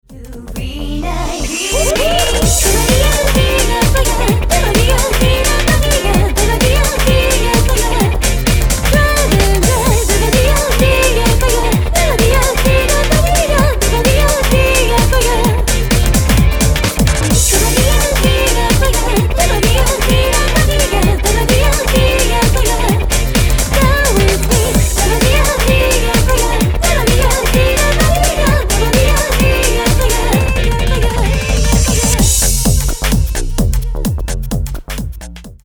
■All music composition , wards , arrengement & guitar play
■Vocal